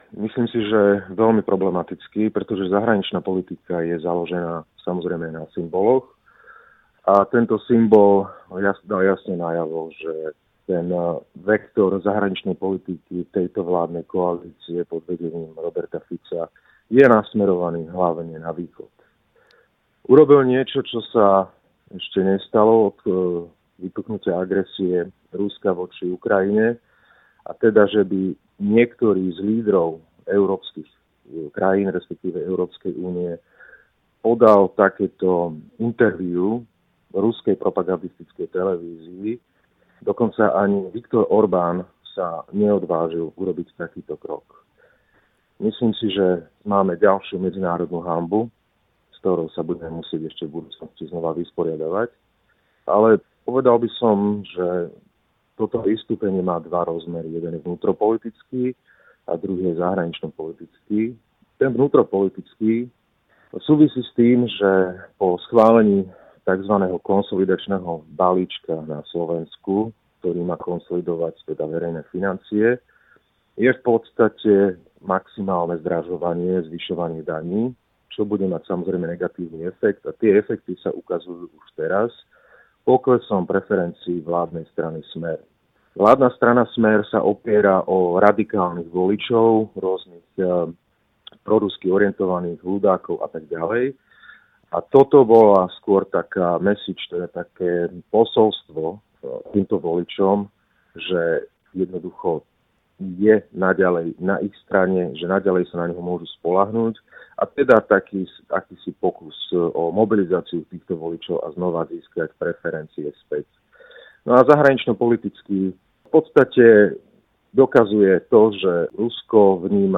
Juraj Krúpa o Robertu Ficovi ve vysílání Radia Prostor